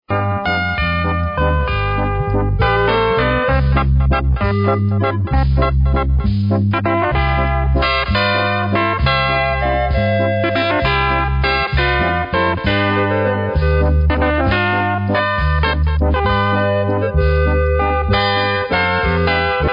Schlager & Volksmusik
Walzer